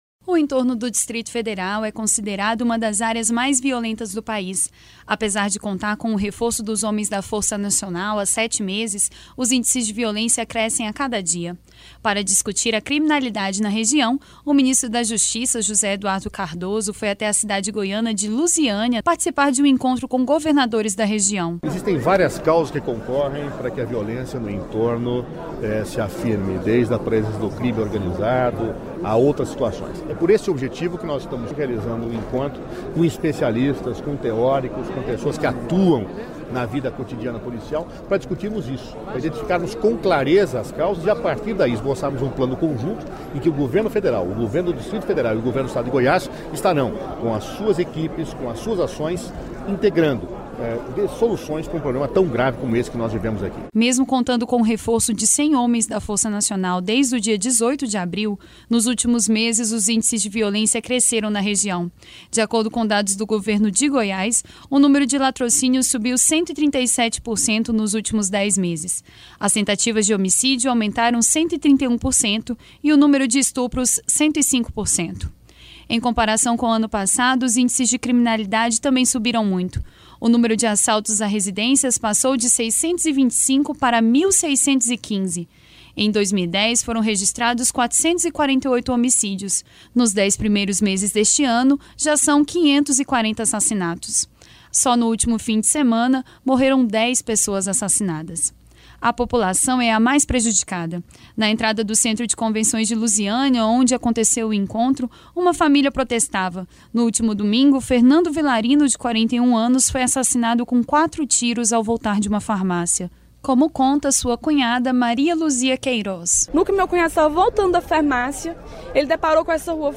O Entorno do Distrito Federal é considerado uma das áreas mais violentas do país. Apesar de contar com o reforço dos homens da Força Nacional, há sete meses, os índices de violência crescem a cada dia. o principal problema da região é o consumo e o tráfico de crack. Reportagem